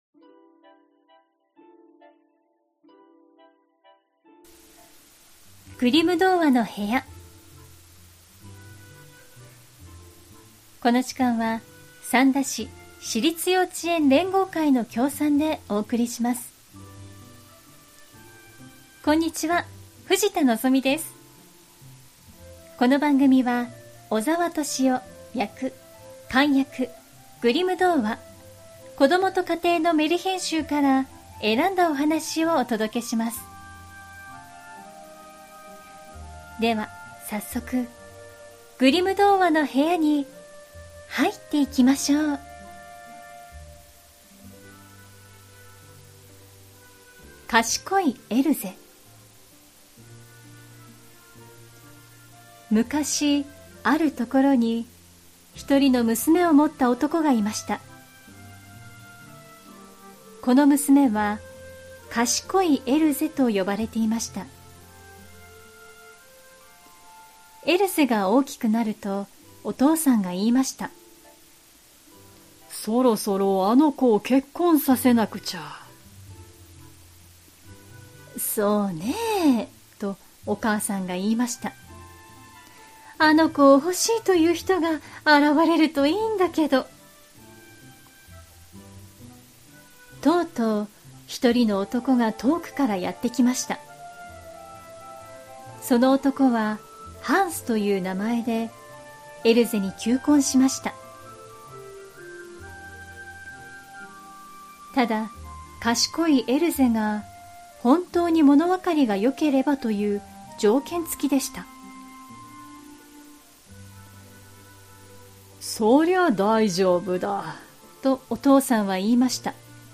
グリム兄弟によって集められたメルヒェン（昔話）を、翻訳そのままに読み聞かせします📖 今回お届けするのは『かしこいエルゼ』。